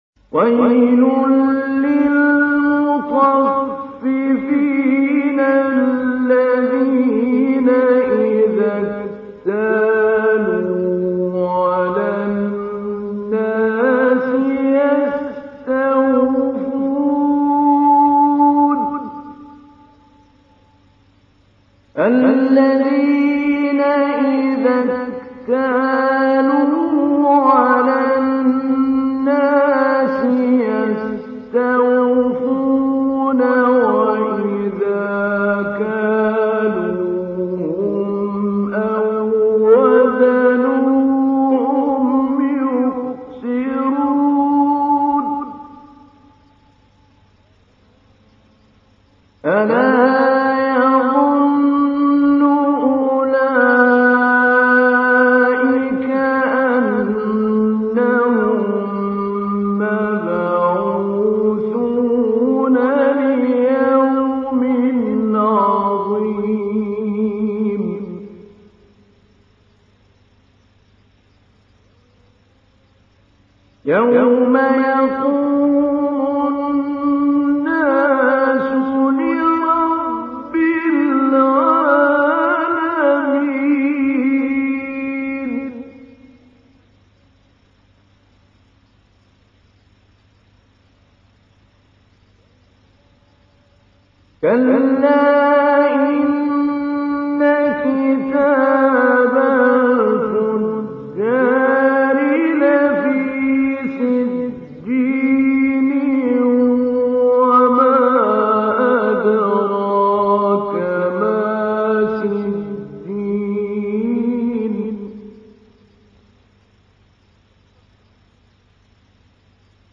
تحميل : 83. سورة المطففين / القارئ محمود علي البنا / القرآن الكريم / موقع يا حسين